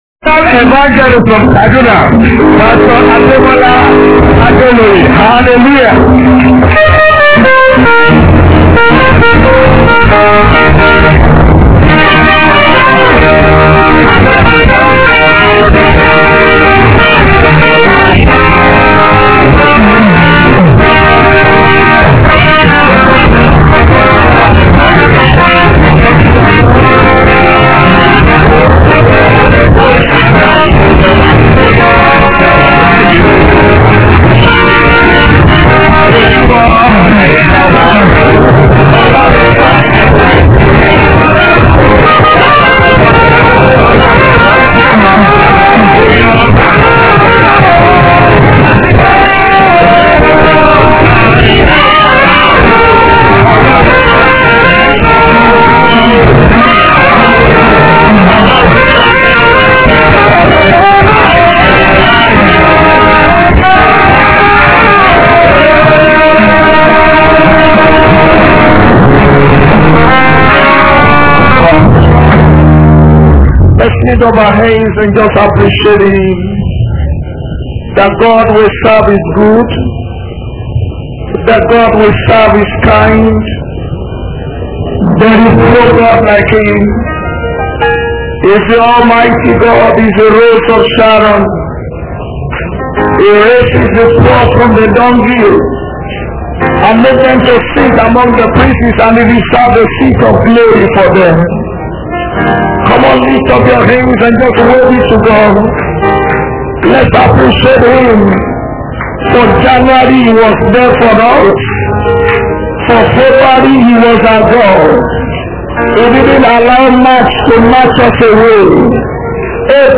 Posted in Thanksgiving Service